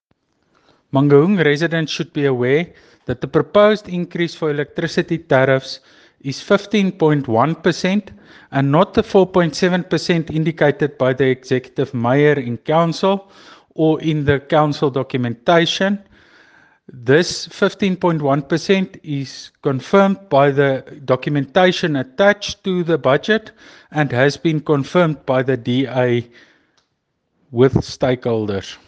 Afrikaans soundbites by Cllr Tjaart van der Walt and
Eng-voice-20.mp3